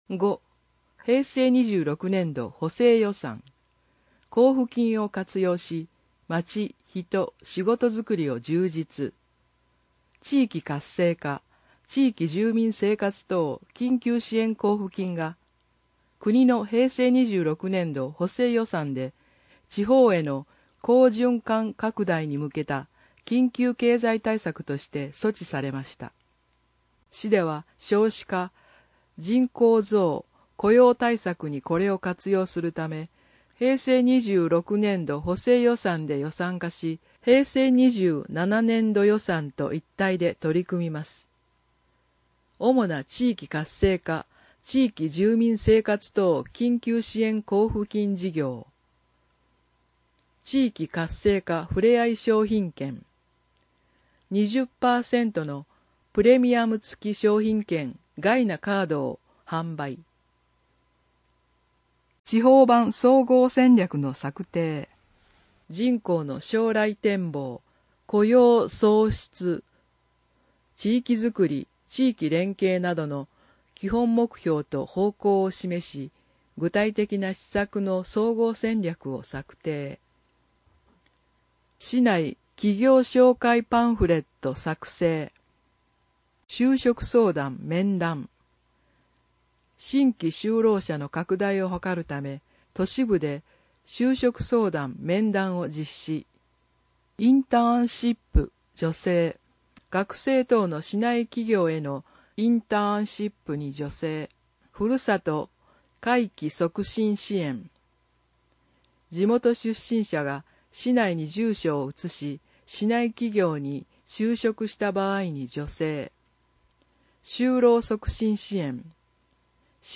朗読　東浦朗読ボランティアグループ・ひとみの会